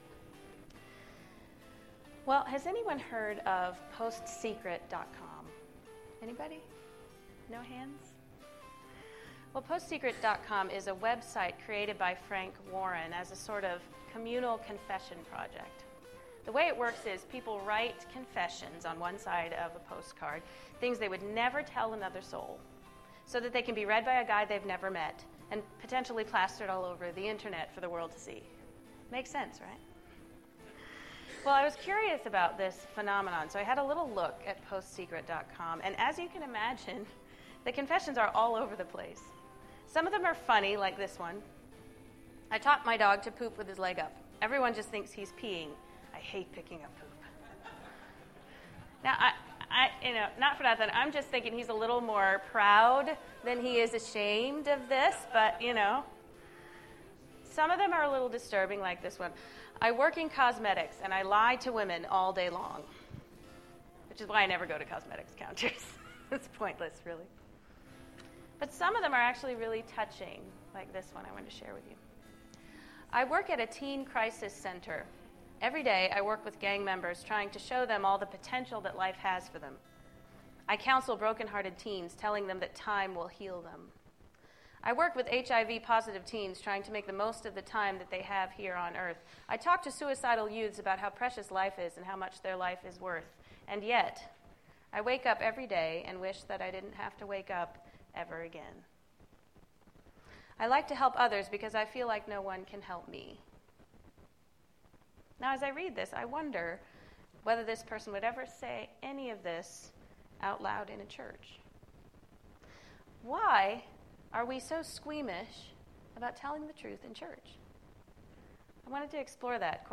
The message was recorded on Wednesday February 11, 2015 during our Seminary Chapel.